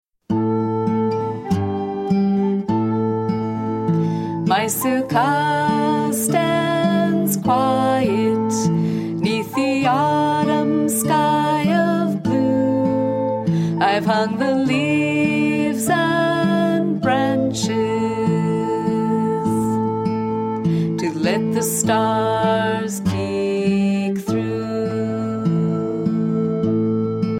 vocals and violin